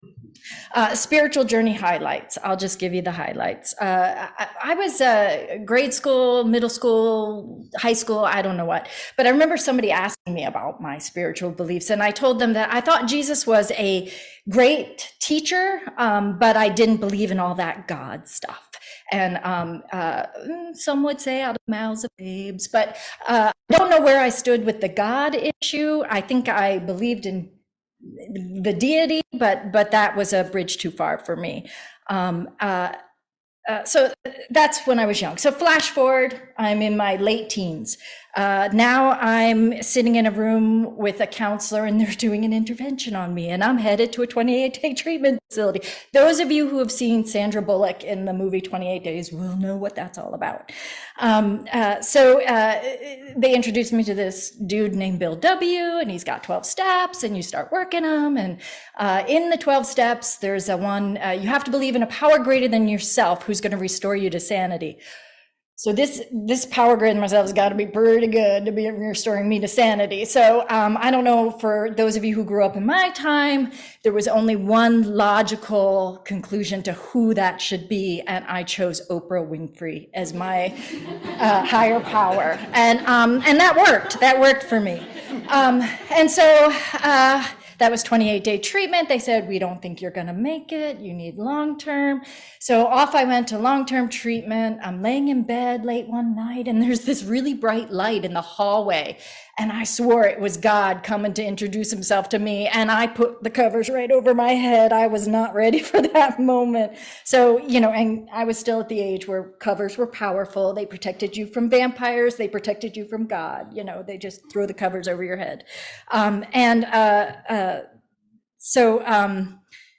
This sermon documents three members of the Unitarian Universalist Church of Loudoun sharing their personal histories and paths toward their current spiritual home.